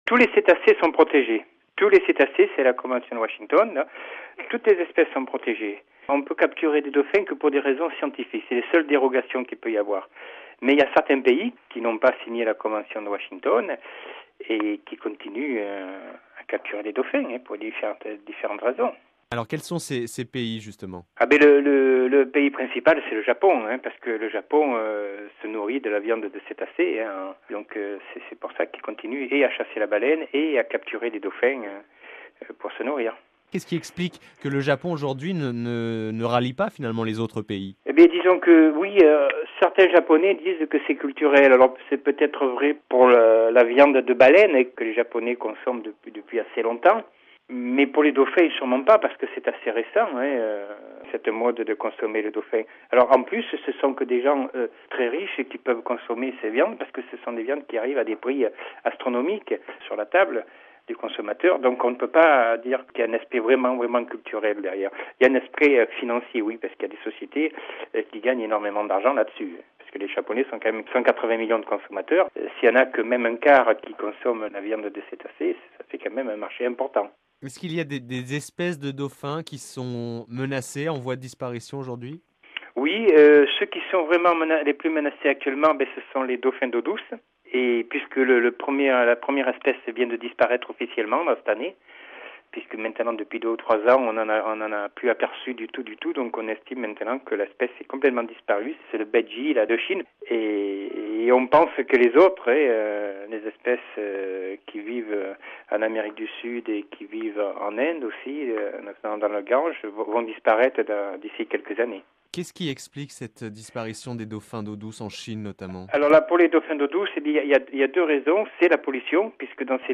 (RV-Vendredi 2 février 2007) Cette année 2007 a été décrétée par l’ONU année internationale pour la protection de ce mammifère marin. On évoque souvent les menaces qui pèsent sur les baleines, mais le dauphin est lui aussi en danger.